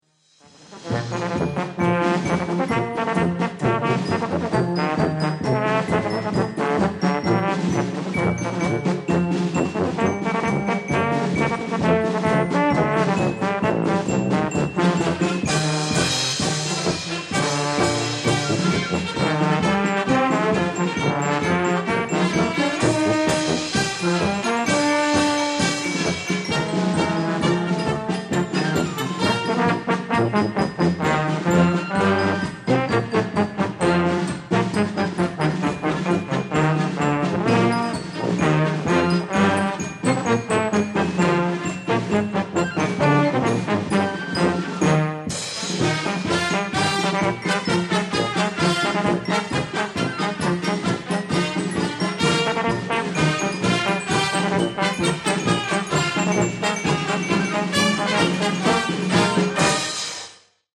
Marches et galops
interpreté par la Fanfare Octave Callot